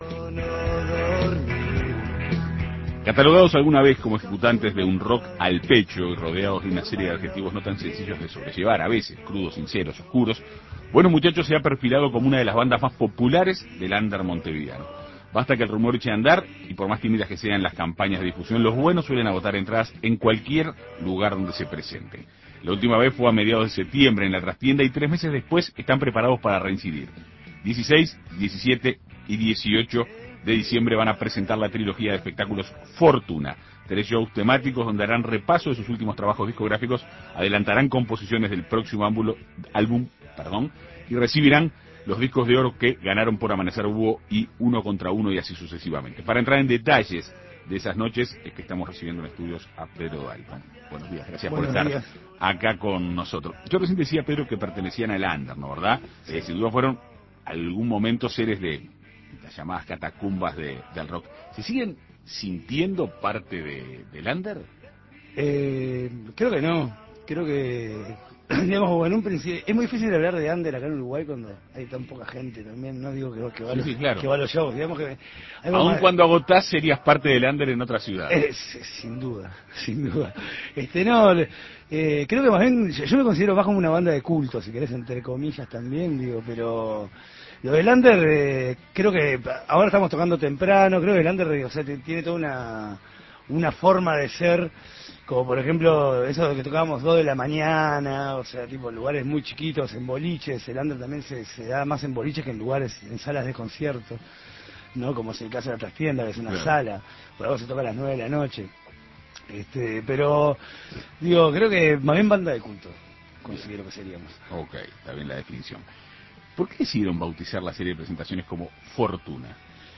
Para conocer detalles de las futuras presentaciones, En Perspectiva Segunda Mañana dialogó con Pedro Dalton, cantante de Buenos Muchachos.